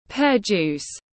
Nước ép lê tiếng anh gọi là pear juice, phiên âm tiếng anh đọc là /peə ˌdʒuːs/
Pear juice /peə ˌdʒuːs/